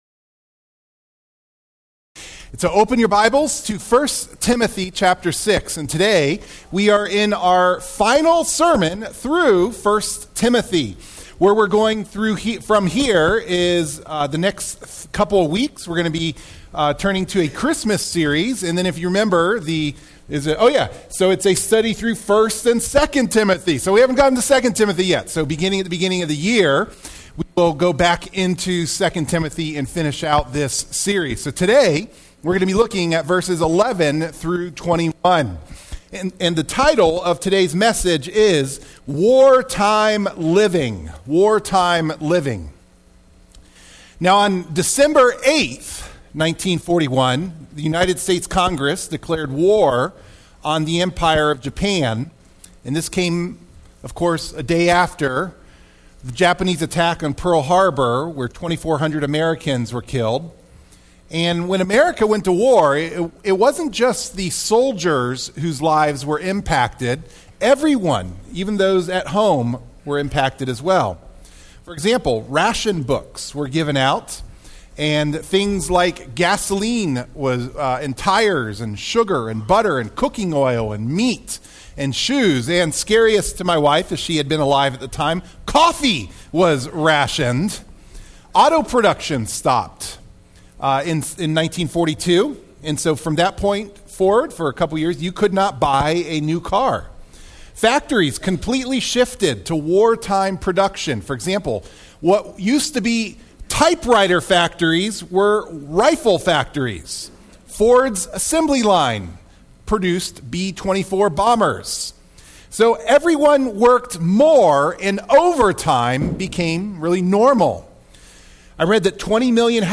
Sermon Clip The message notes for the sermon can be downloaded by clicking on the “save” button.